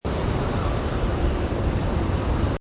howlingwind3.mp3